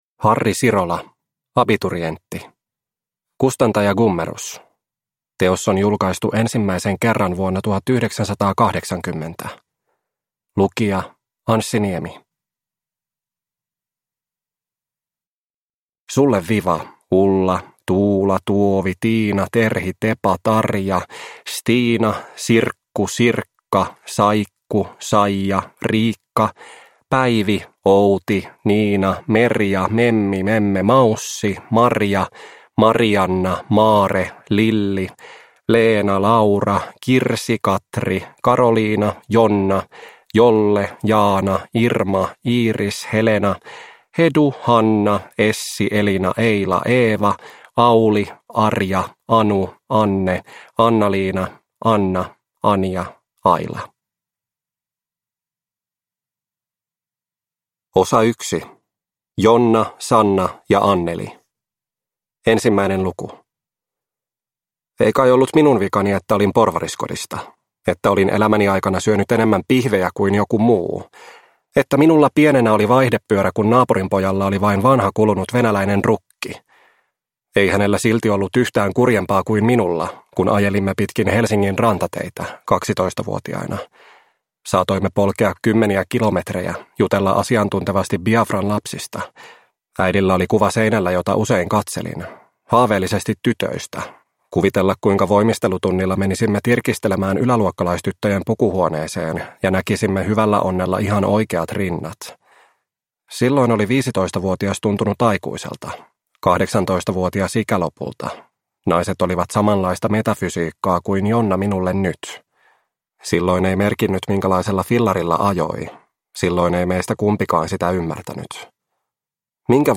Abiturientti – Ljudbok – Laddas ner